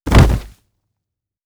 04_书店内_主角摔倒.wav